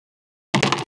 Index of /traerlab/AnalogousNonSpeech/assets/stimuli_demos/jittered_impacts/small_rubber_longthin_yellowrubbertubing